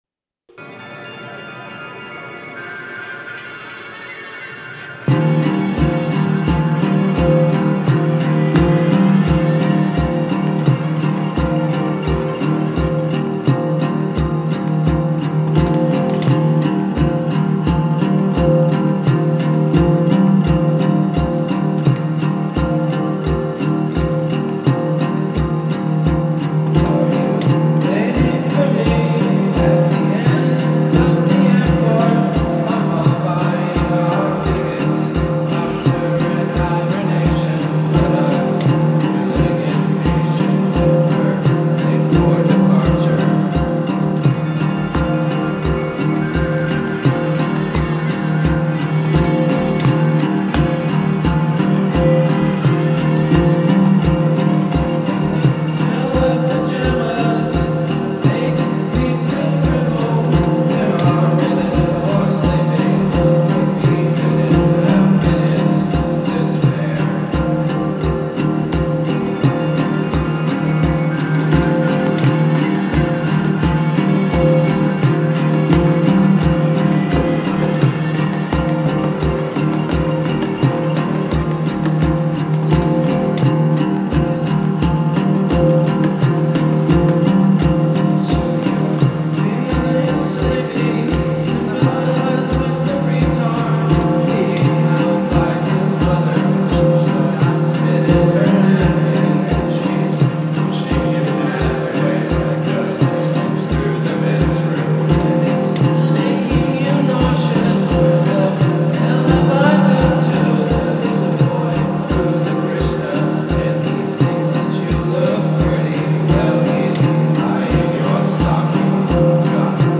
the psychedelic, droning repetition
airy vocals